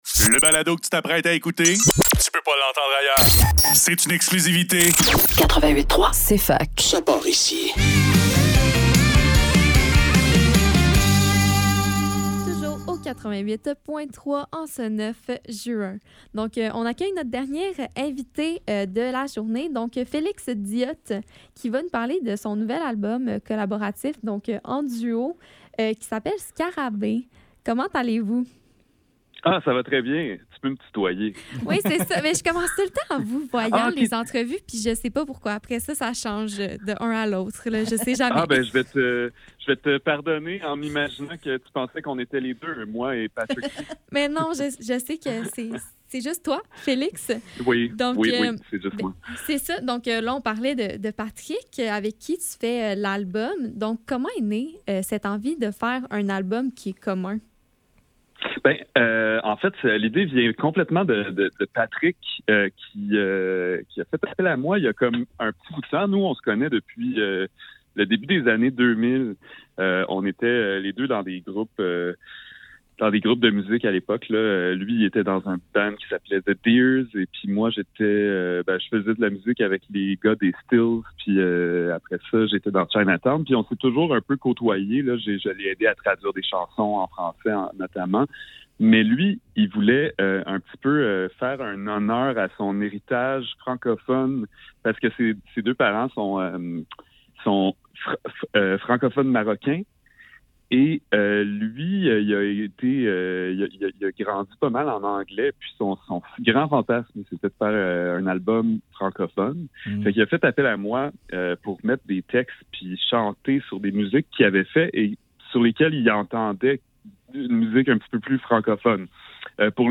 Cfaktuel - Entrevue